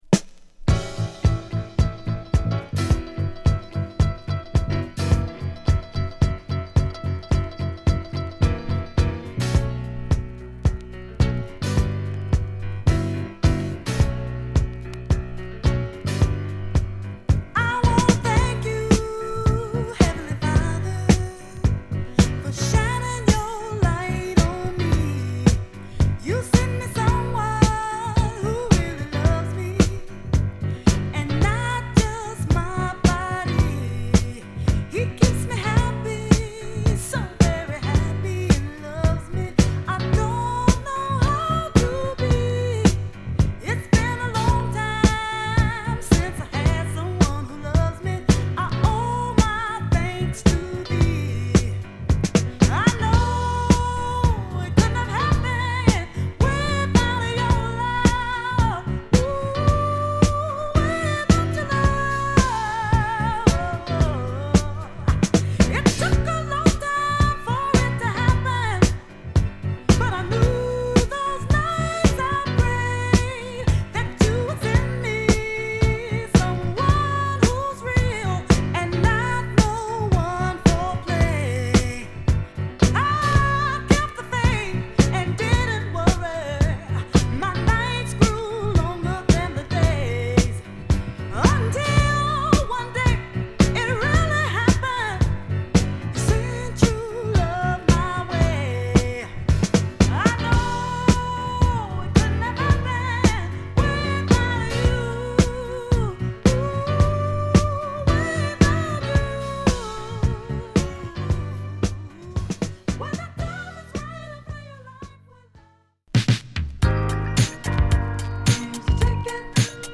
アーバン〜モダンなミディアムダンサー
＊SideBノイズ有り。